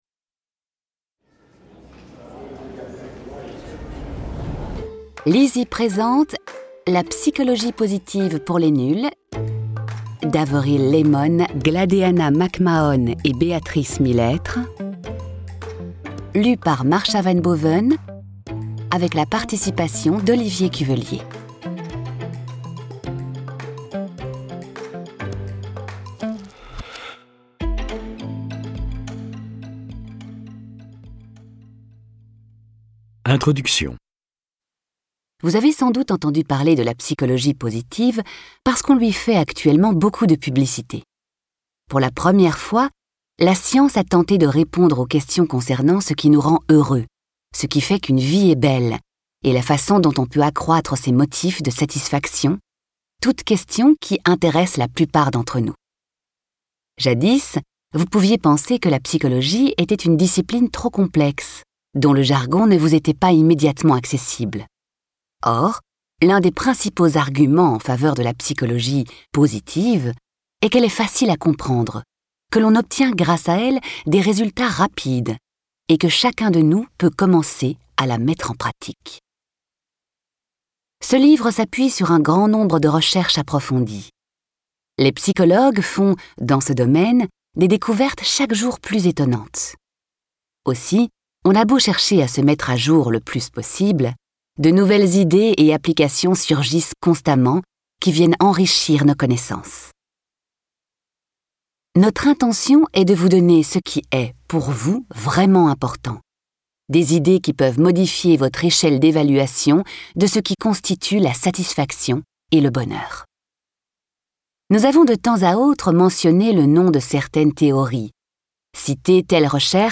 Ce livre audio vous aidera à traverser la vie autrement : un zeste d'exercices, un zeste de malice, un zeste de délice pour découvrir la pensée positive !